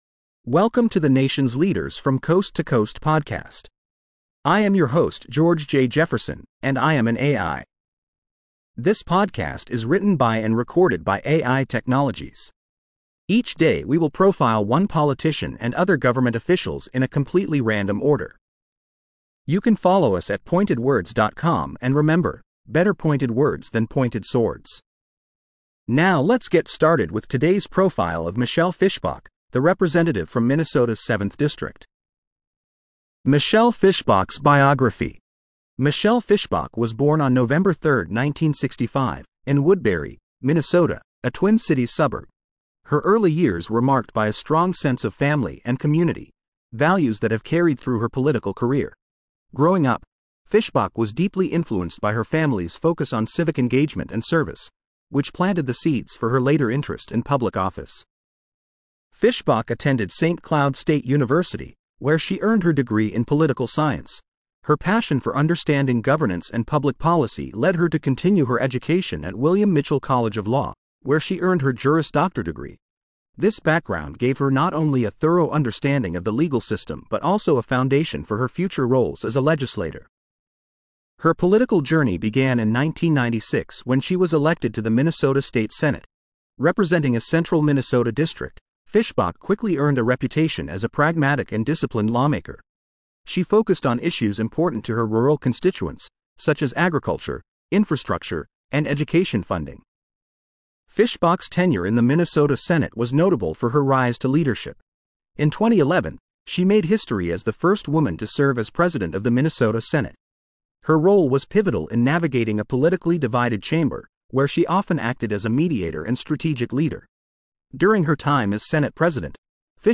AI Profile of Rep. Michelle Fischbach